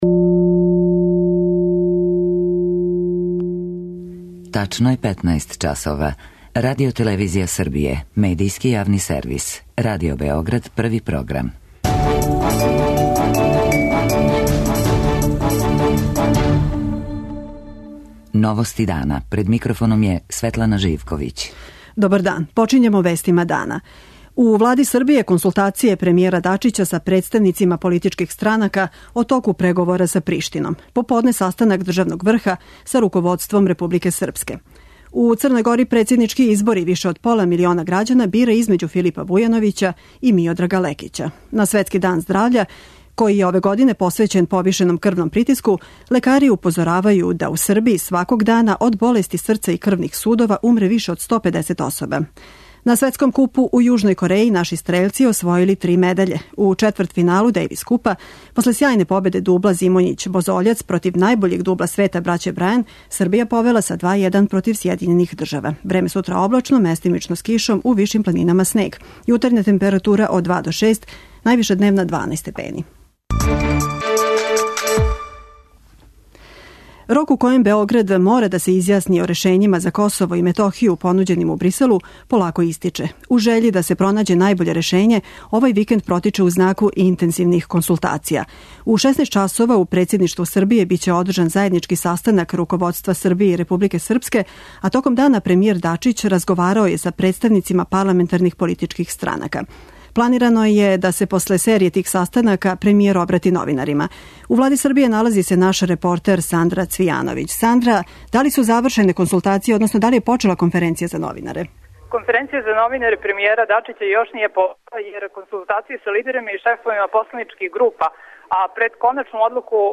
Тим поводом гост Новости дана је начелник Сектора за ванредне ситуације Предраг Марић.